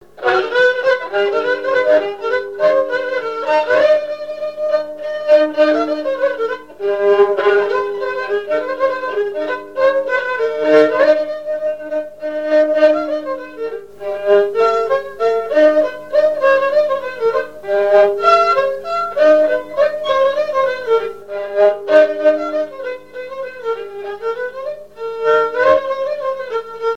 Mémoires et Patrimoines vivants - RaddO est une base de données d'archives iconographiques et sonores.
danse : java
Genre strophique
Pièce musicale inédite